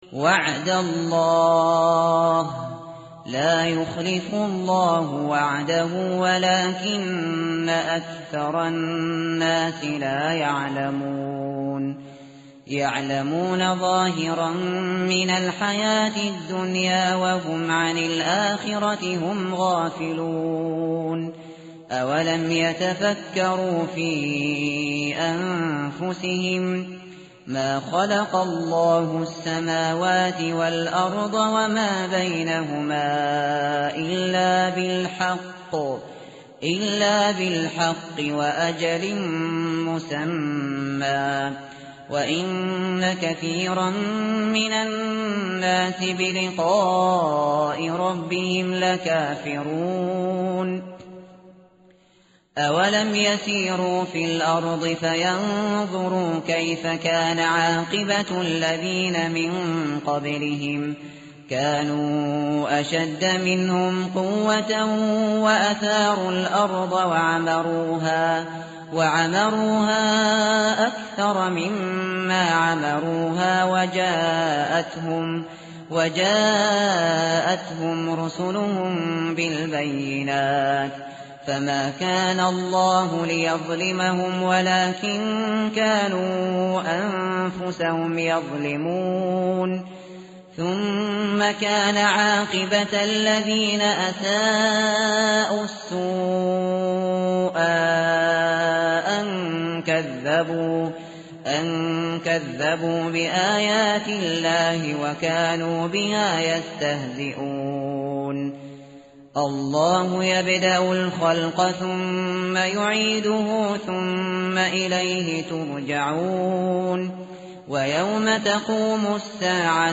tartil_shateri_page_405.mp3